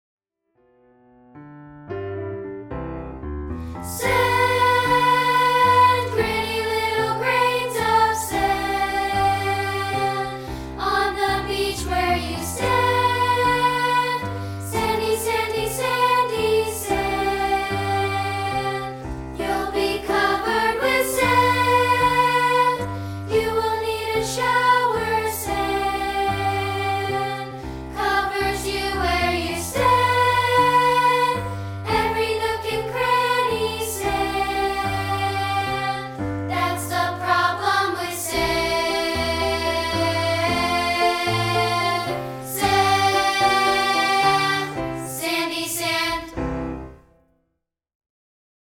No. 5 and made it available as a free rehearsal track.